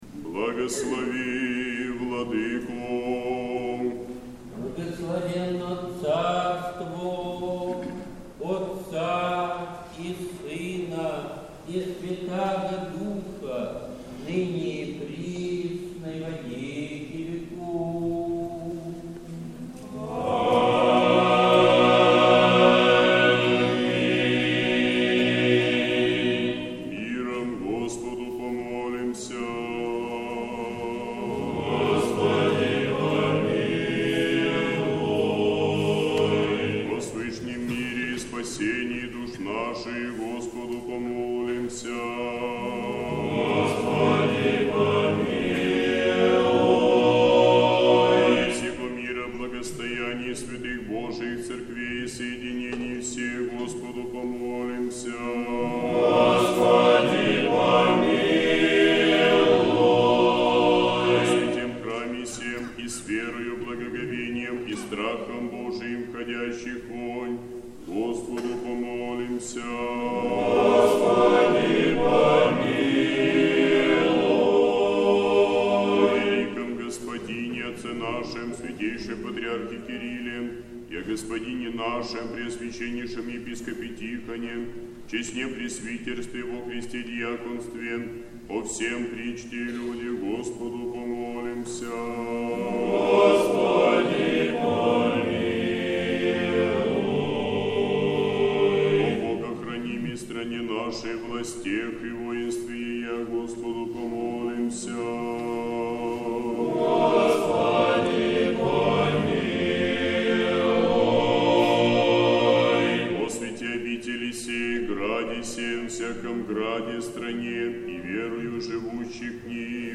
Празднование Казанской иконы Божией Матери. Сретенский монастырь. Божественная литургия. Хор Сретенской Духовной Семинарии.